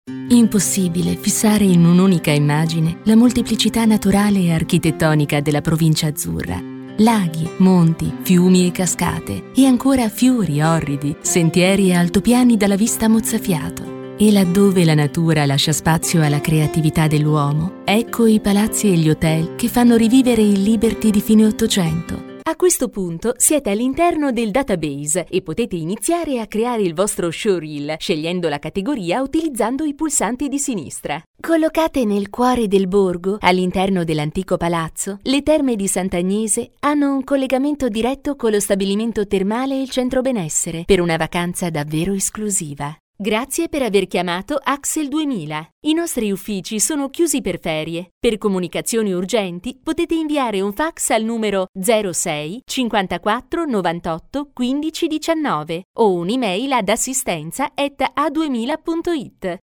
Mother-tongue Italian professional Voiceover Talent.
Sprechprobe: Werbung (Muttersprache):